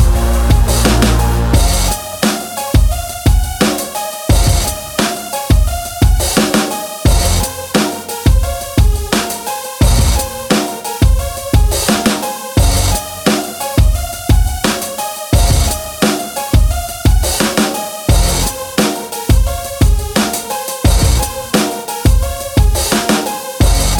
Without Rap R'n'B / Hip Hop 3:49 Buy £1.50